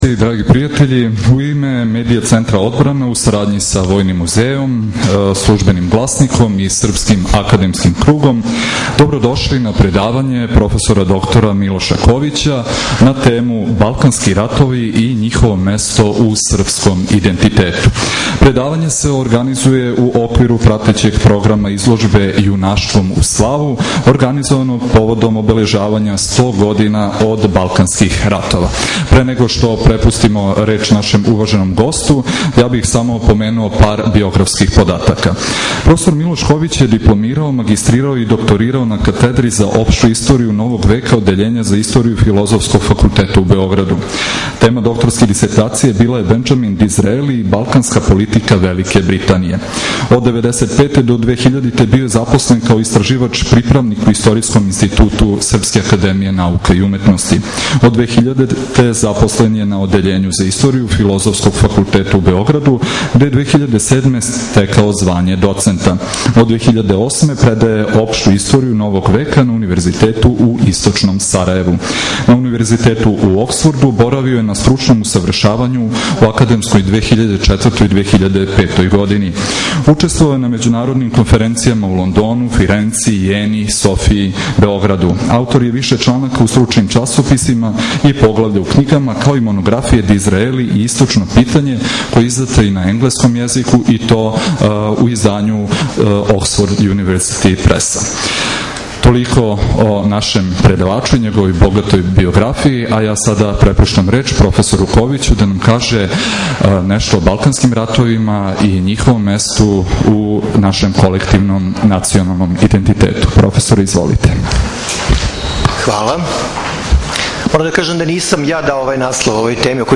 ДОКУМЕНТА Предавање (75 мин, 34 МБ) mp3